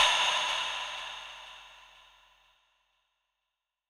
ENE Ahh FX Bb.wav